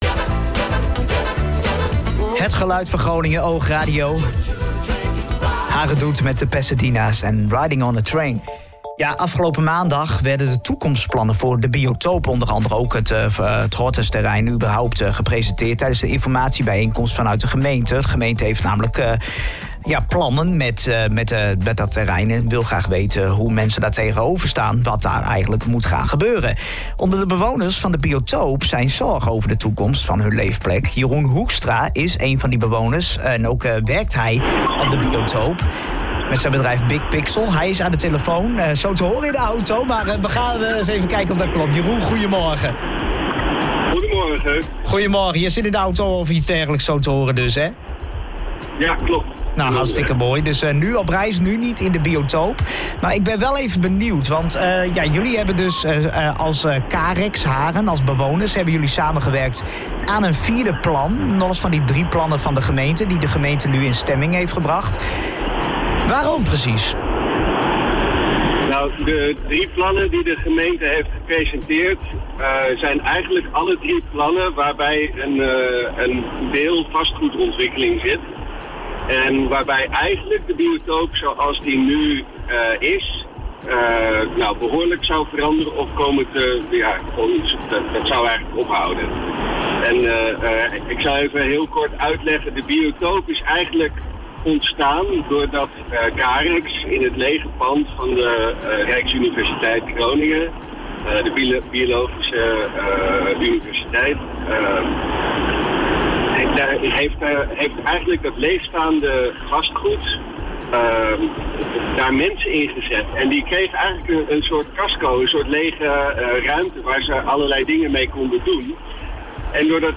Interview-Biotoop.mp3